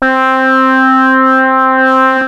OSCAR PAD 01 4.wav